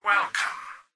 "Welcome" excerpt of the reversed speech found in the Halo 3 Terminals.